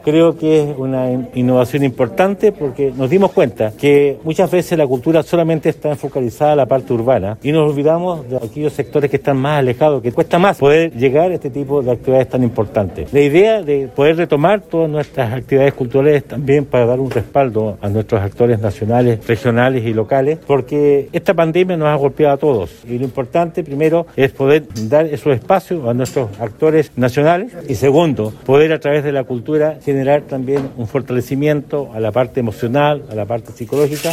El alcalde Emeterio Carrillo, quien además preside la Corporación Cultural de Osorno, explicó que se busca que la cultura llegue a las zonas rurales.